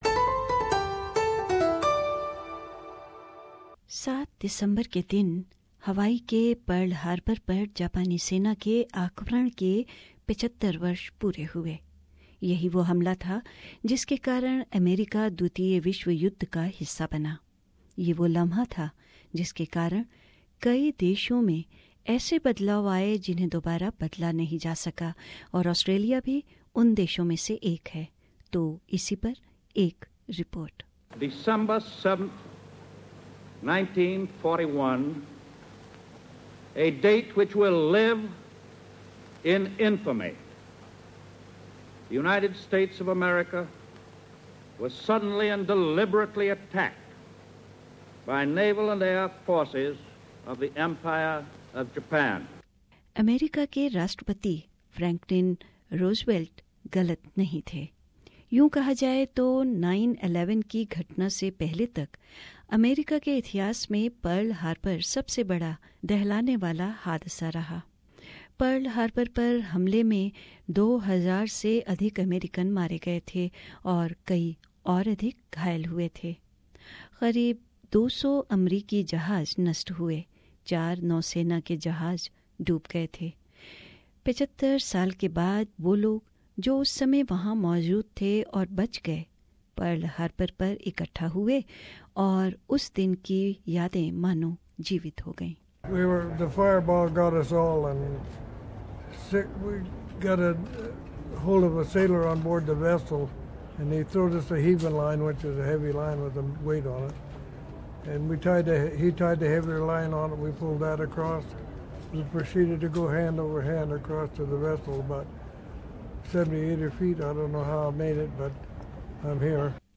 Feature: Pearl Harbour